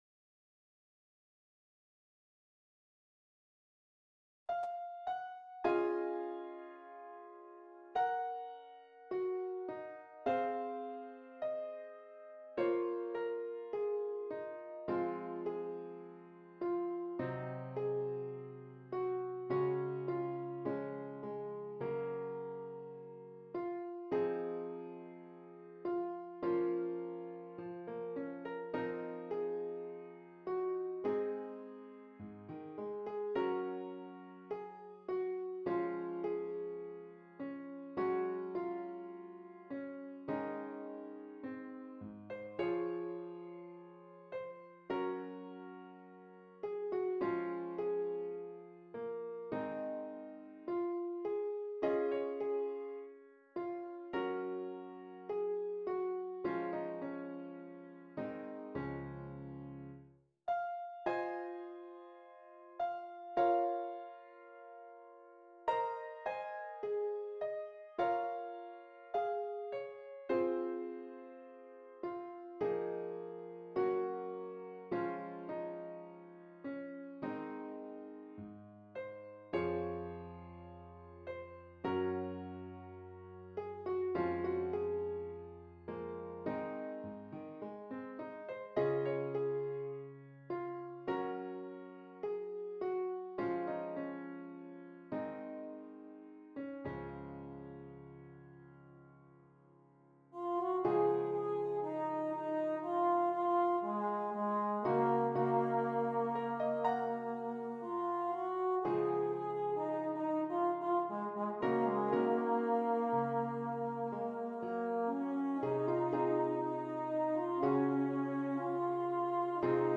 Flugel horn and Keyboard